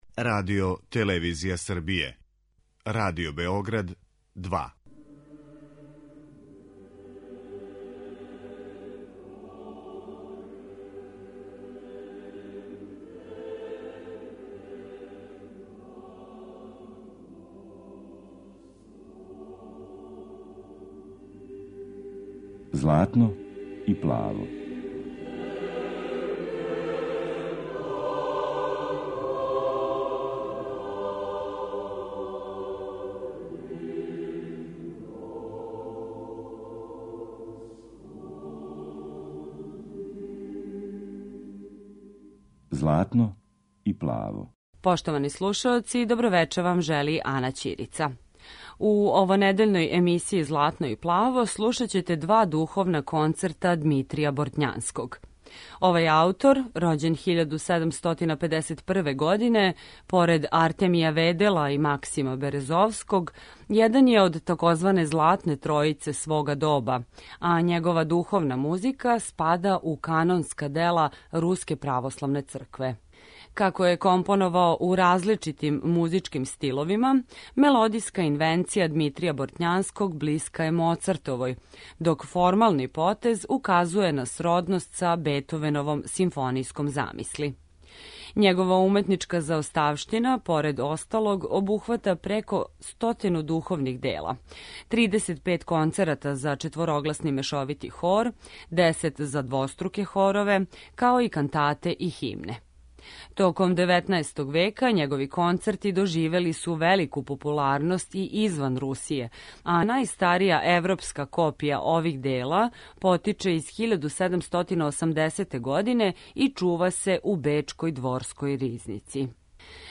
У емисији Златно и плаво слушаћете његова два духовна концерта (бр. 26. и 27), у извођењу хора Руске државне капеле којим диригује Валериј Пољански.